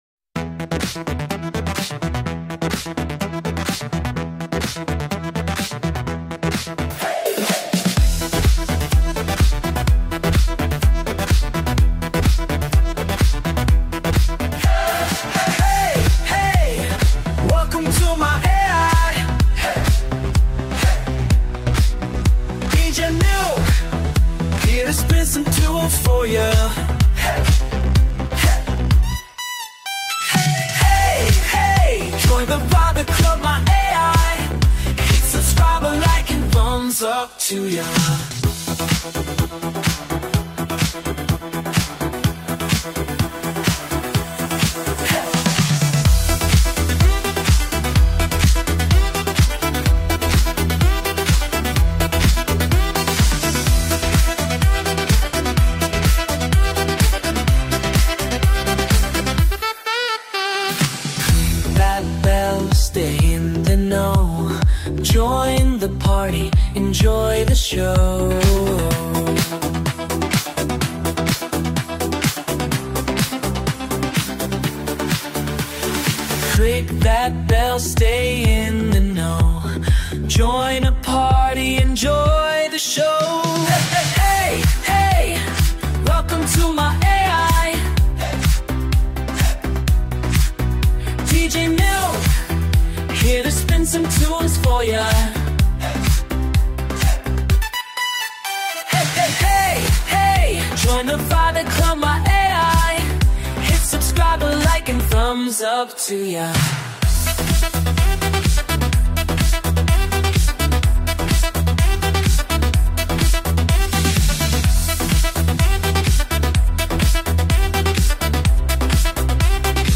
(Remix)
Music & Vocals: AI